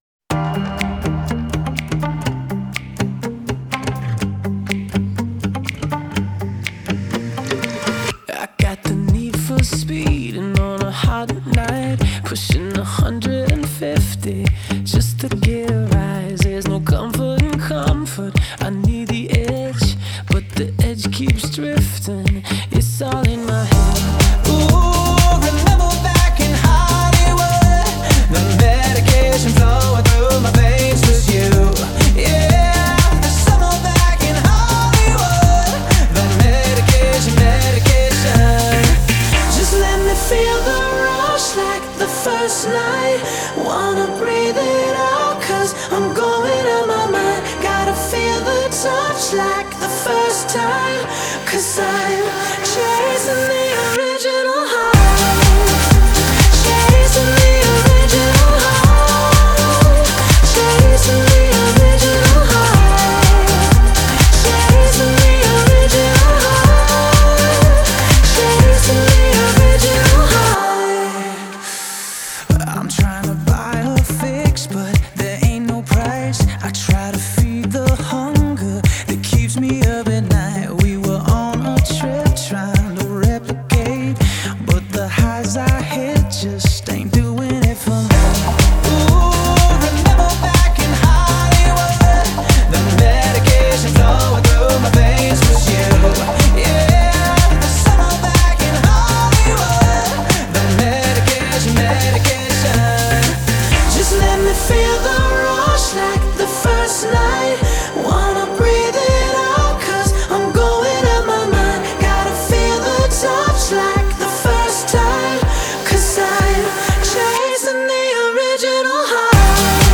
Genre: Pop, Dance